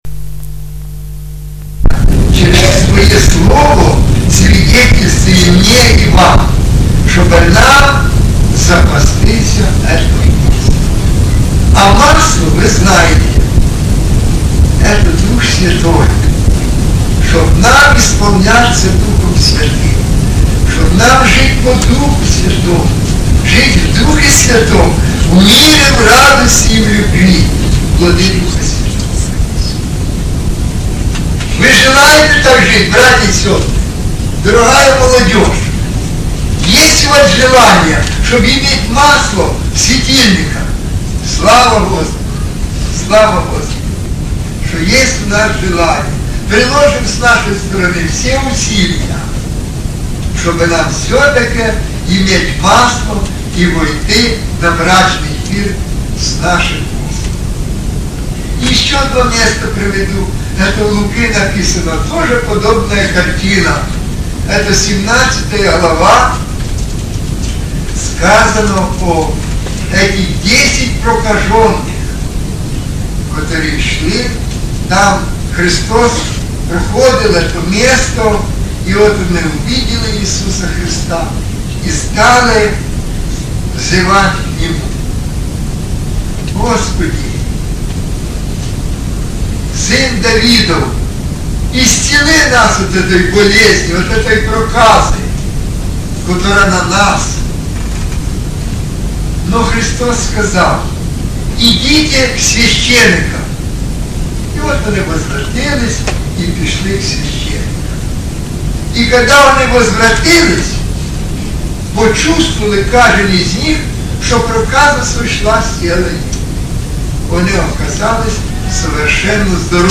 Категория: Аудио проповеди